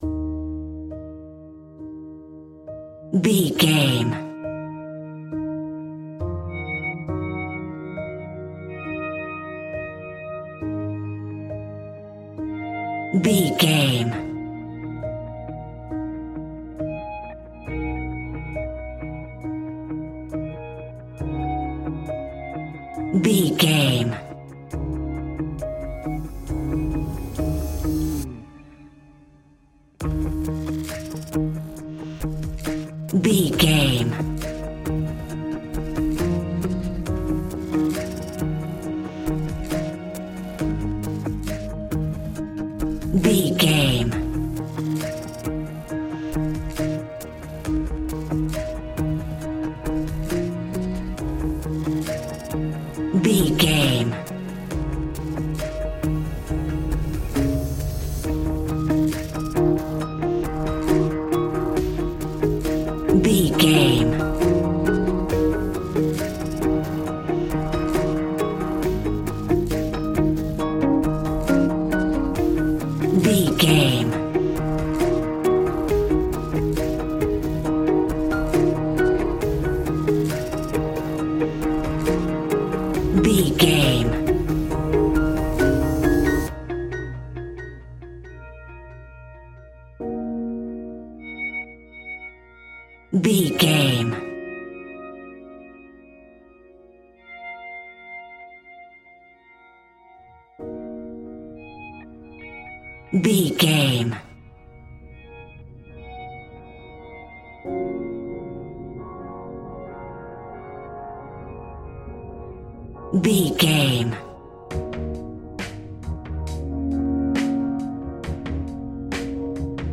Ionian/Major
D♯
electronic
techno
synths
synthwave
instrumentals